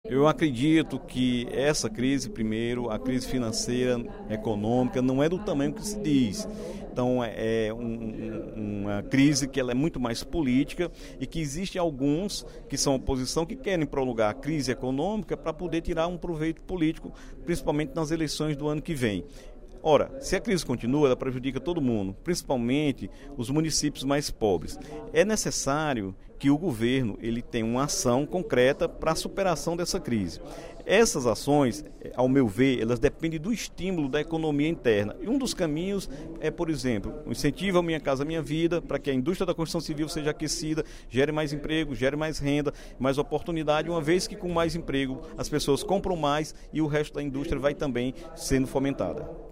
O deputado Dr. Santana (PT) defendeu, em pronunciamento no primeiro expediente da Assembleia Legislativa desta sexta-feira (25/09), a organização da sociedade para enfrentar a crise econômica no Brasil.